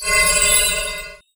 Magic_SpellHeal03.wav